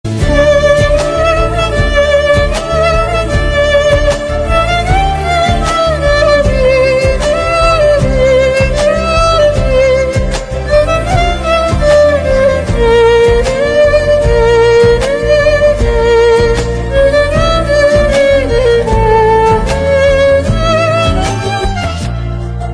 an Indian romantic ballad